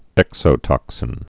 (ĕksō-tŏksĭn)